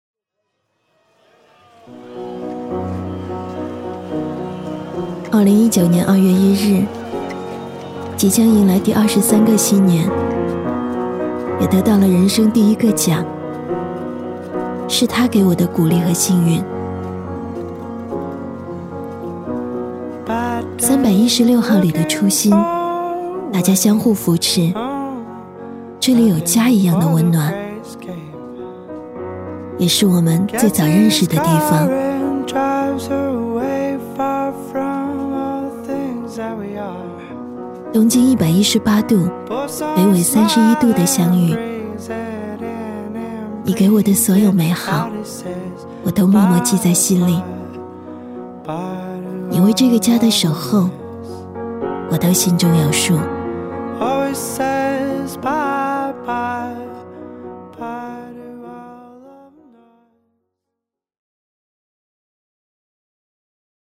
女粤19_专题_企业_公司宣传简介_年轻-新声库配音网
配音风格： 年轻 欢快 温柔